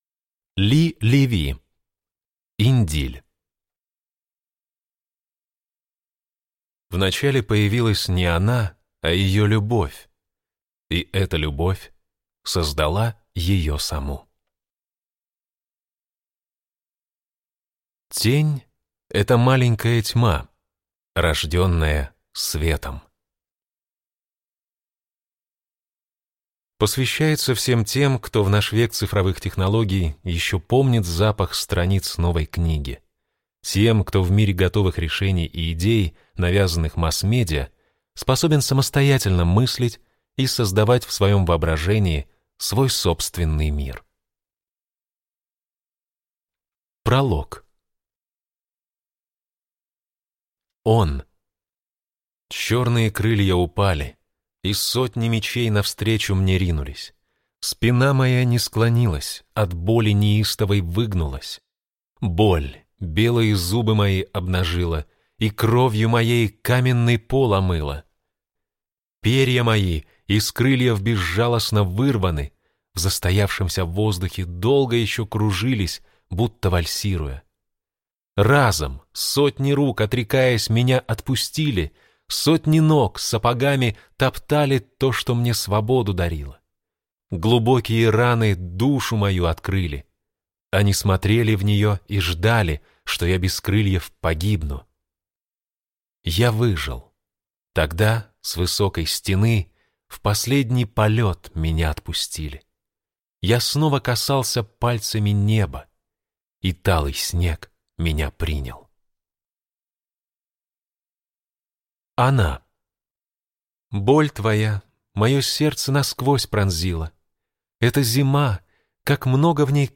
Аудиокнига Индиль | Библиотека аудиокниг